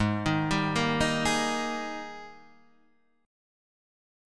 G# Major 7th Suspended 2nd